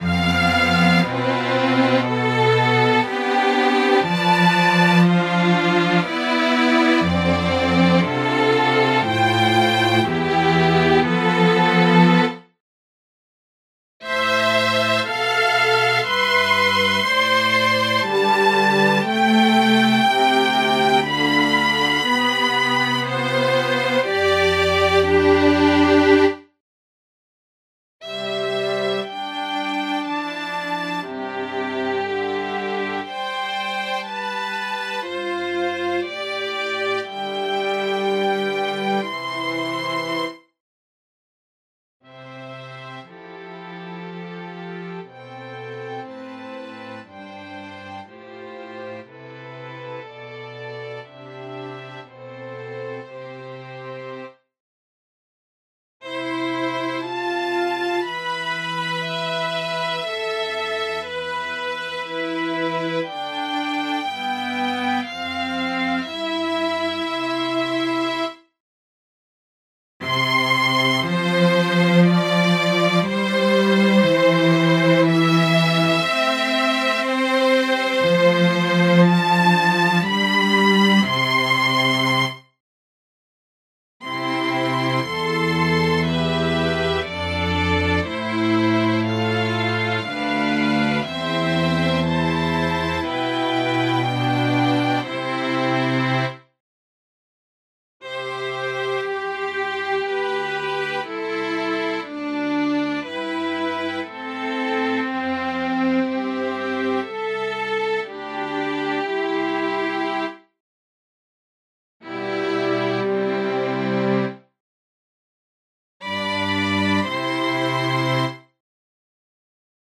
I have been fascinated by all four of the movements, which the authors call “Experiments”, but Experiment No. 2: four-part first-species counterpoint (the Adagio of the ILLIAC Suite) immediately and particularly caught my attention.
Select a device with a receiver in the ExpTwo program, set the MIDI program (“instrument”) of your synthesizer to some strings for best results (I use String Ensemble in GarageBand / Full Strings in Logic Pro), but you're on your own from there.
• The Adagio composed and played at the World Premiere of ExpTwo in Graz, Austria, on 2023-05-23, in MIDI and